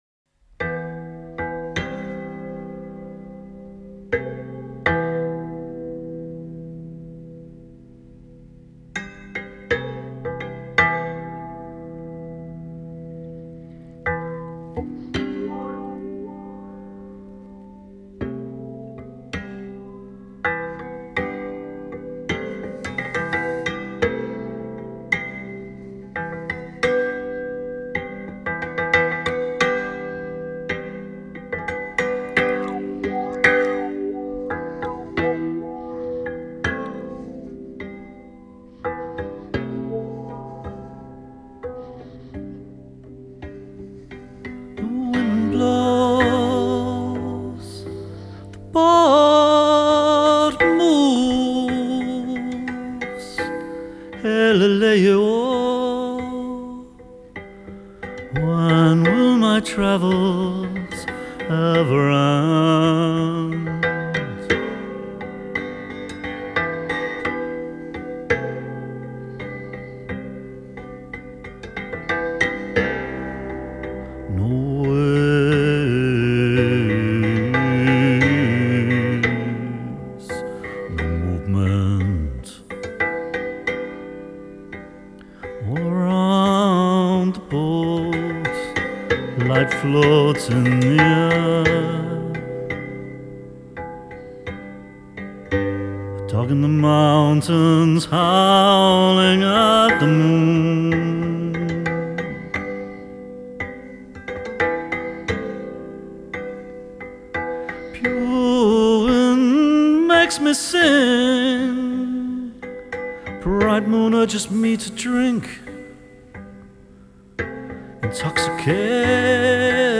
Genre: World Music
Recording: Windwood Studios